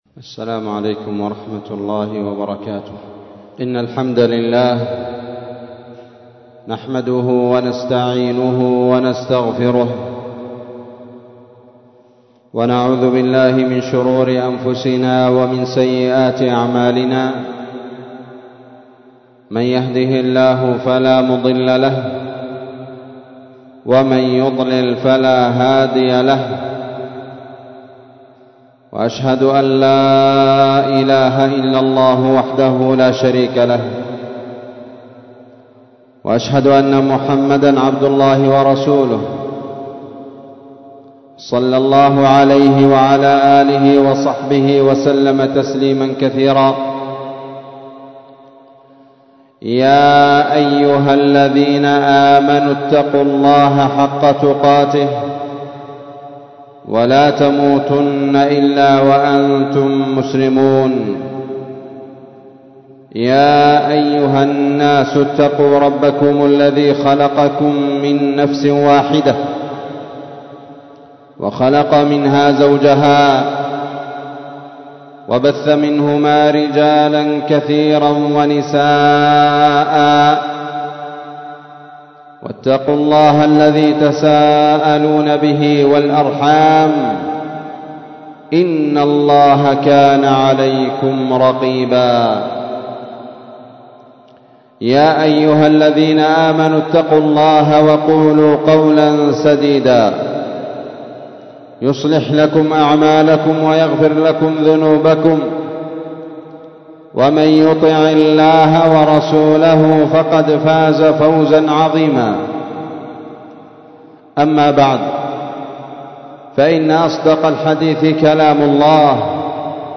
خطبة قيمة